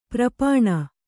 ♪ prapāṇa